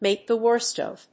アクセントmàke the wórst of…